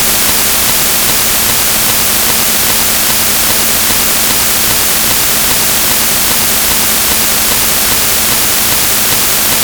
Tonmitschnitte (aufgezeichnet über Mikrofoneingang am Notebook):
Es ist deutlich am Knackgeräusch hörbar, wie die Sequenz sich wiederholt.